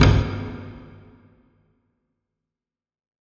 Taiko Drum 1 (Coldest Winter).wav